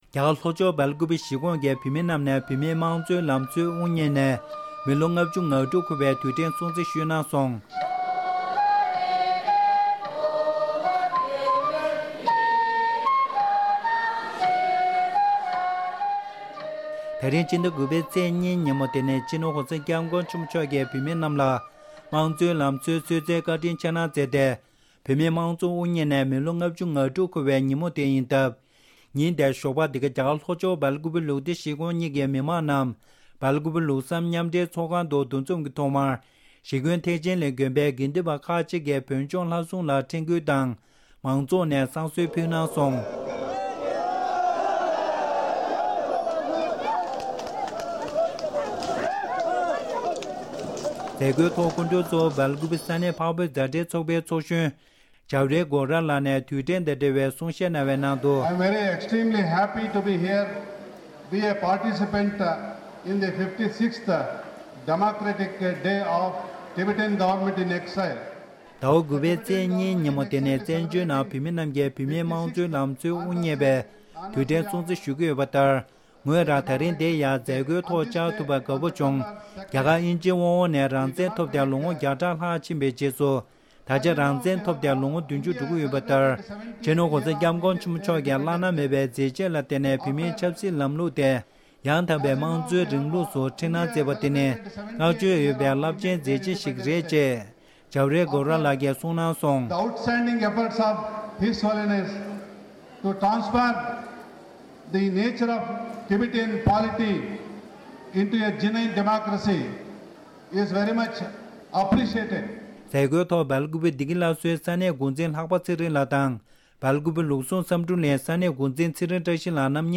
རྒྱ་གར་ལྷོ་ཕྱོགས་སུ་མང་གཙོའི་དུས་ཆེན་སྲུང་བརྩི་གནང་བ། སྒྲ་ལྡན་གསར་འགྱུར།